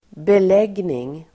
Uttal: [bel'eg:ning]